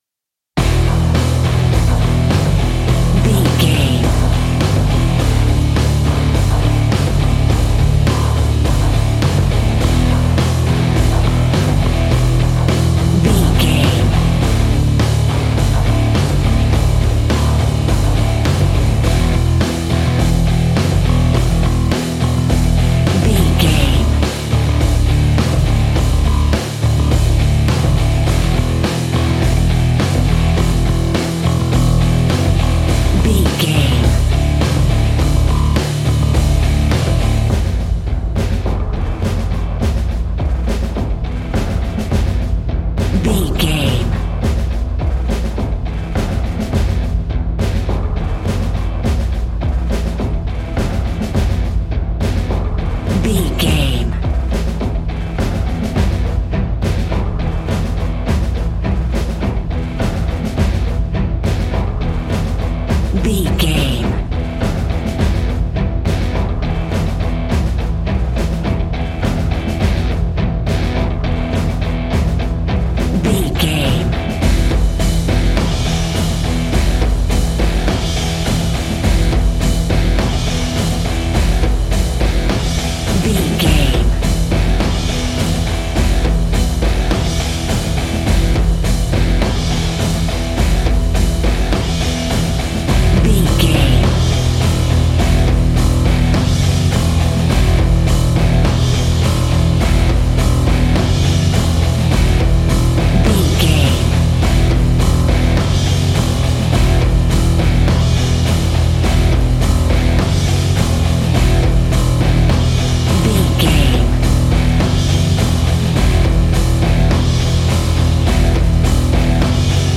Epic / Action
Fast paced
Aeolian/Minor
hard rock
instrumentals
Heavy Metal Guitars
Metal Drums
Heavy Bass Guitars